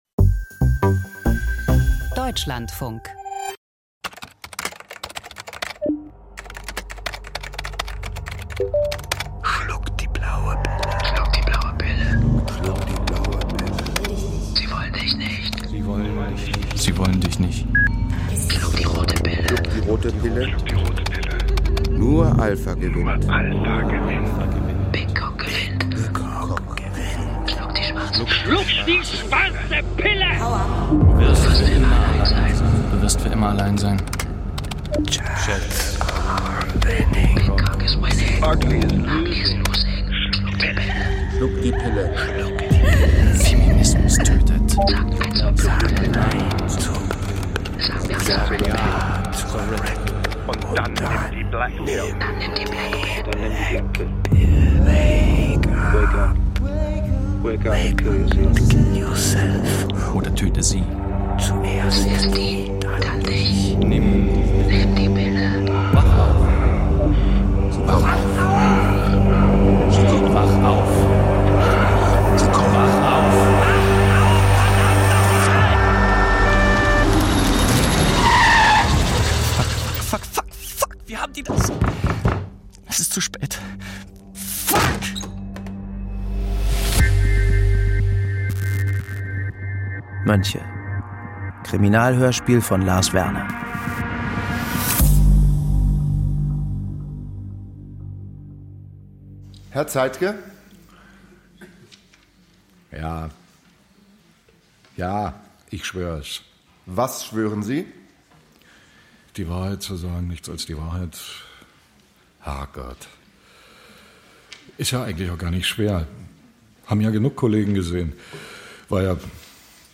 Krimihörspiel